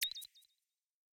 song-ping-variation-2.wav